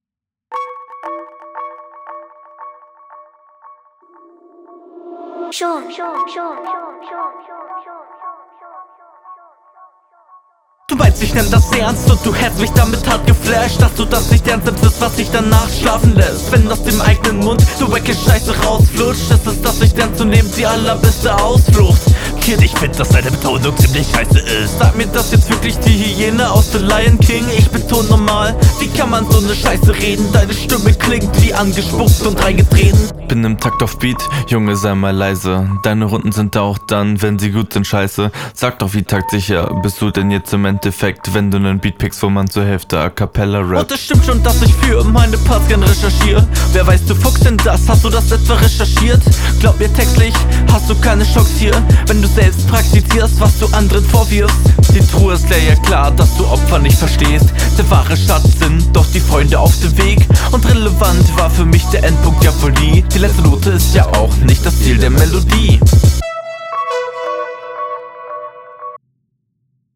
bissl wenig zurückgeschossen, aber der beat wurde im drumlosen teil gut gebattlet. die meisten konter …